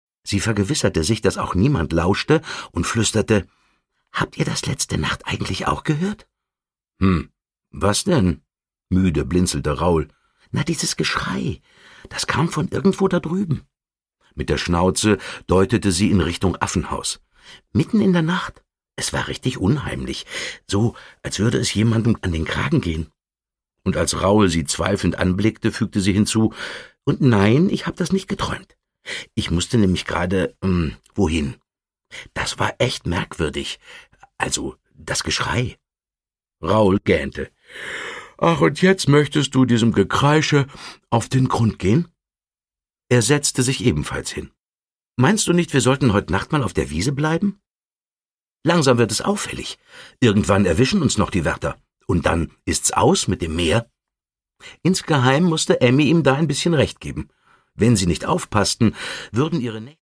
Produkttyp: Hörbuch-Download
Gelesen von: Dietmar Bär
Dietmar Bär nimmt es bei den Wasserschweinen stimmlich mit einem ganzen Zoo auf!